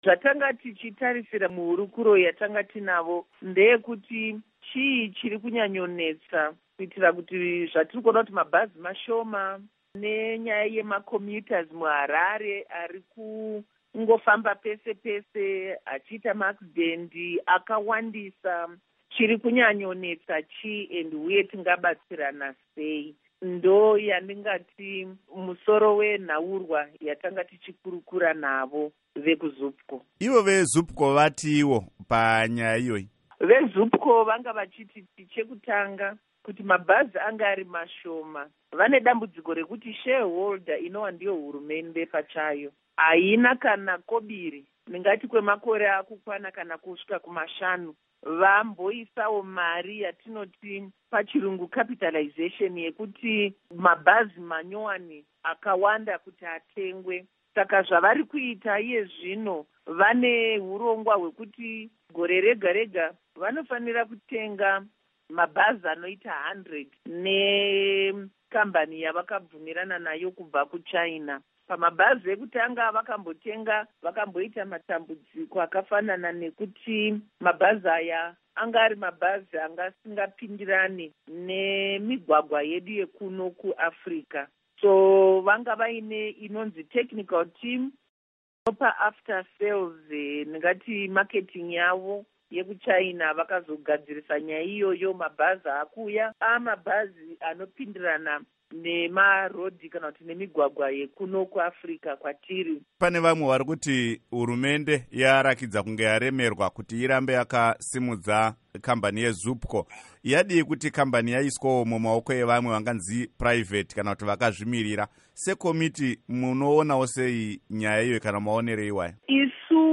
Hurukuro naMuzvare Irene Zindi